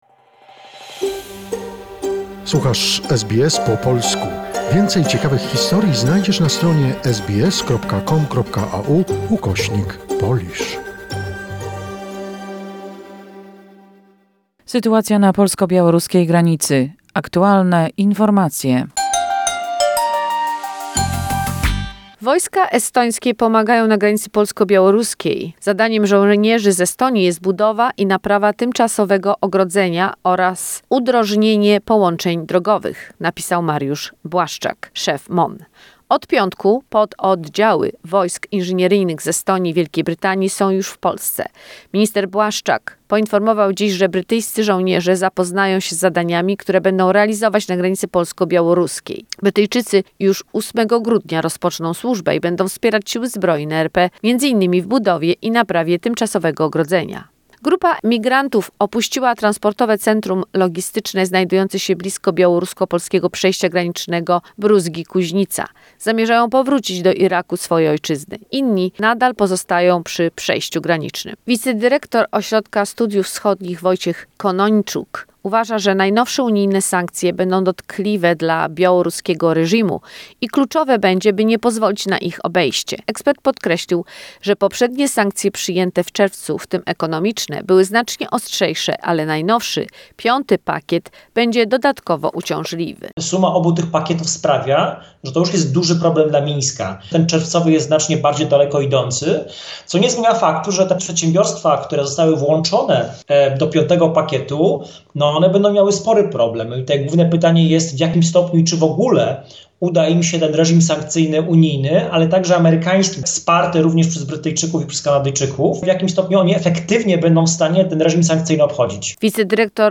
The most recent information and events regarding the Poland-Belarus situation, a short report prepared by SBS Polish.